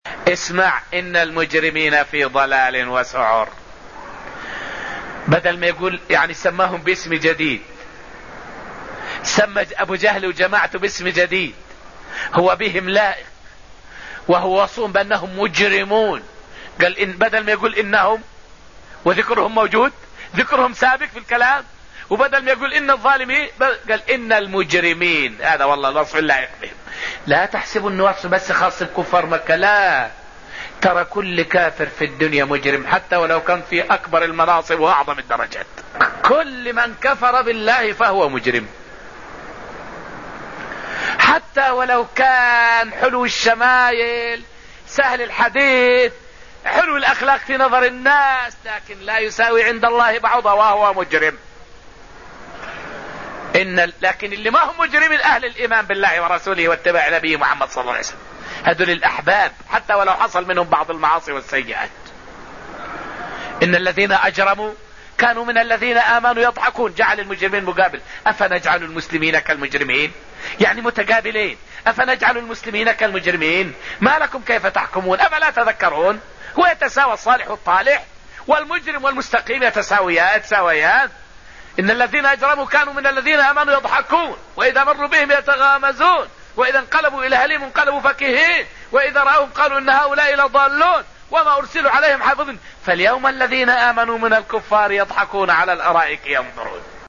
فائدة من الدرس السابع من دروس تفسير سورة الرحمن والتي ألقيت في المسجد النبوي الشريف حول ضلال الكفار في إنكار الحكمة من الخلق.